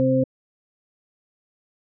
metronome.wav